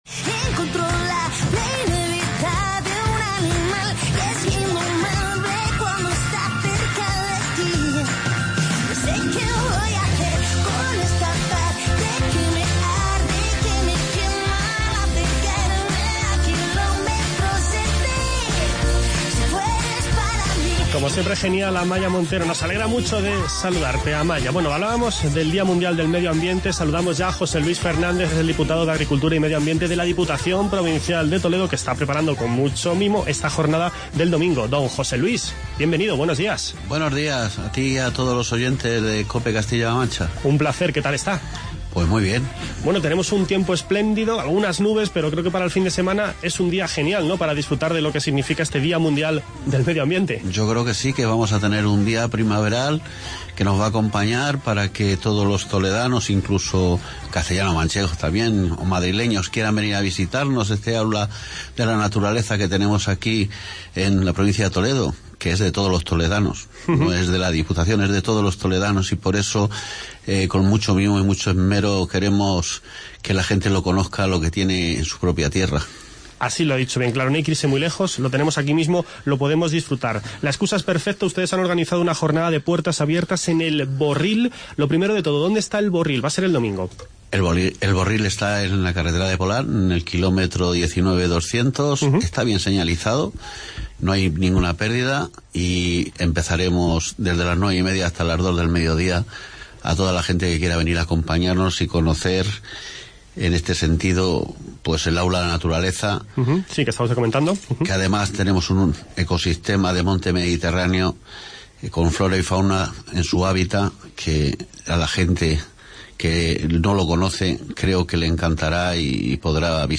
Hoy charlamos sobre el Día Mundial del Medio Ambiente que celebraremos este próximo domingo, 5 de junio. Nos acompaña en los estudios de COPE José Luis Gutiérrez, diputado de Agricultura y Medio Ambiente de la Diputación Provincial de Toledo.